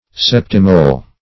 Search Result for " septimole" : The Collaborative International Dictionary of English v.0.48: Septimole \Sep"ti*mole\, n. [L. septem seven.]